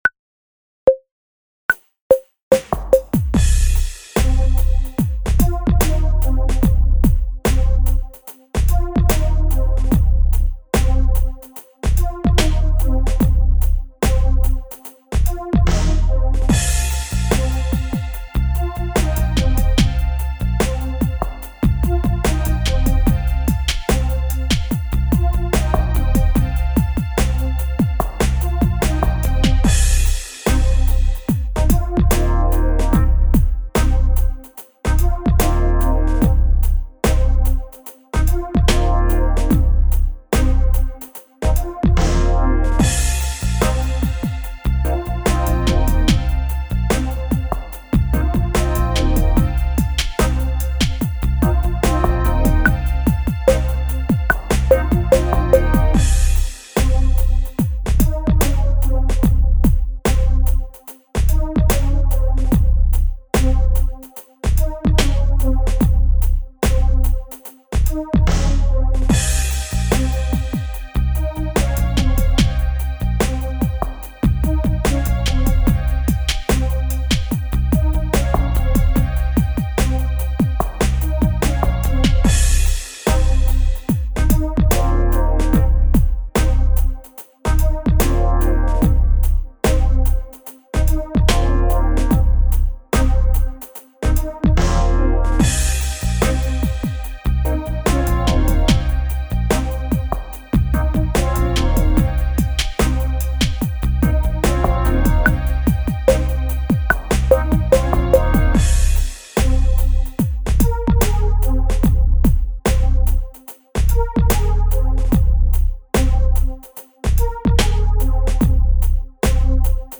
Pues bien, para que puedas practicar todo esto, he compuesto dos Backing tracks para improvisar.
Son secciones de 16 compases para cada modo menor —Dórico, Frigio y Eólico—, y otros 16 para el modo Locrio; donde se modula a la tonalidad de Db, es decir, tonalidad en la que el VII, modo Locrio, es Cø.
En este Backing Track he incluido un cambio rítmico para hacerlo más interesante: En cada modo, los 8 primeros compases tienen ritmo shuffle y los 8 segundos no. ¡A ver si lo aprecias!
También hay una claqueta que avisa del cambio de modo.
Modos-CmLocrio.mp3